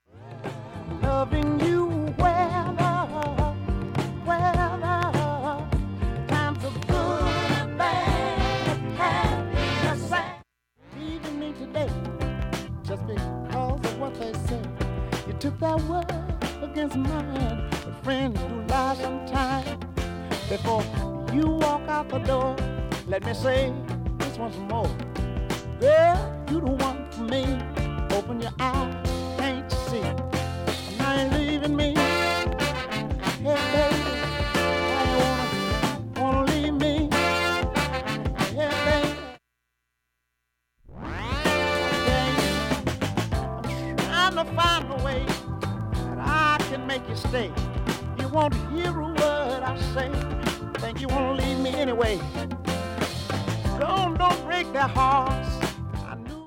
音質良好全曲試聴済み。
A-1終盤にかすかなプツが4回出ます。
単発のかすかなプツが8箇所